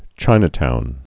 (chīnə-toun)